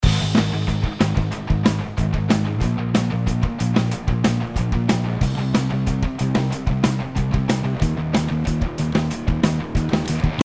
ピッキングに関わりそうな帯域の上下を区切って3バンド構成にし、中央の帯域に対してひずみを与え、レベルを少し持ち上げました。
Saturn 2をかけた音：
前者はオケに埋もれた感じがいていましたが、Saturn 2をかけることによって音が結構前に出てくる感じがしますね。